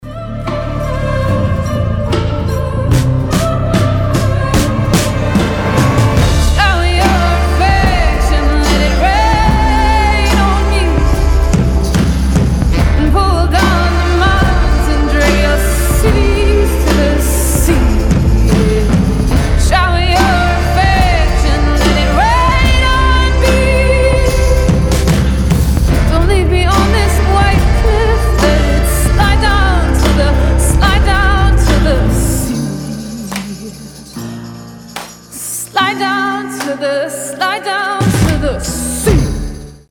• Качество: 320, Stereo
ритмичные
indie pop
alternative
indie rock
необычные
сильный голос